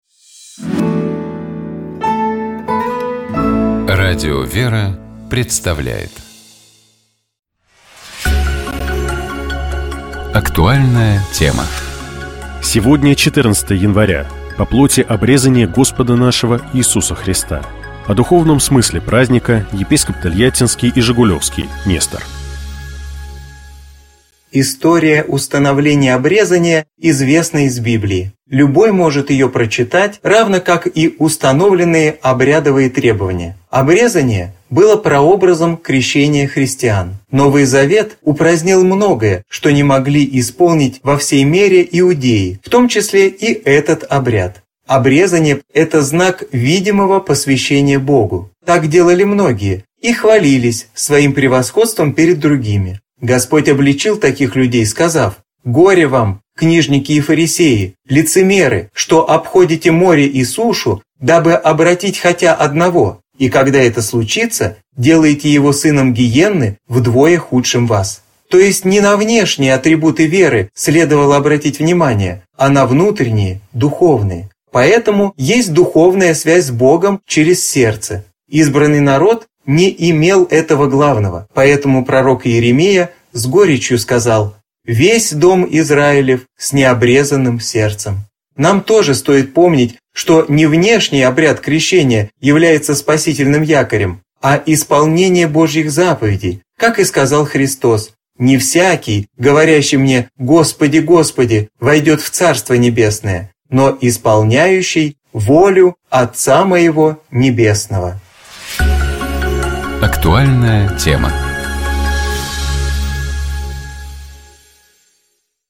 О духовном смысле праздника — епископ Тольяттинский и Жигулёвский Нестор.